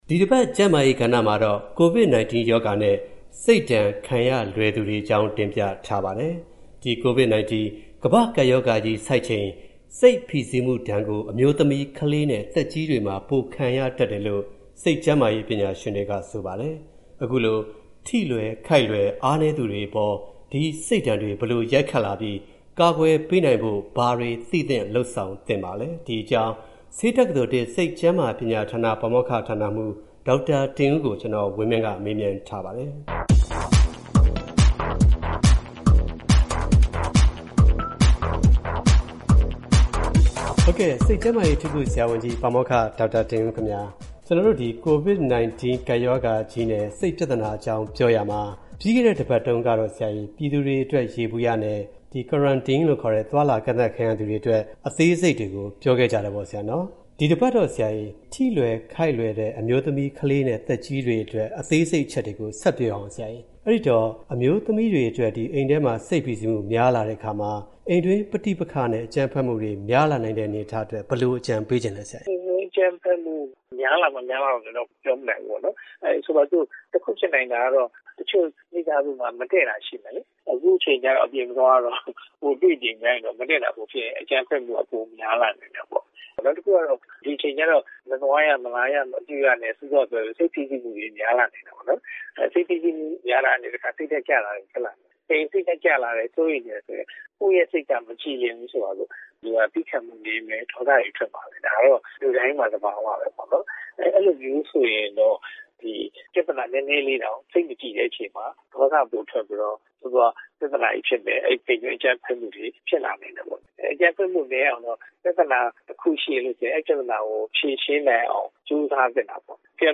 ဆက်သွယ်မေးမြန်း တင်ပြထားပါတယ်။